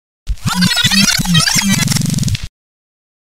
SONIDO REBOBINAR DJ
Efecto de sonido de rebobinar para sesión DJ
sonido-rebobinar-dj.wav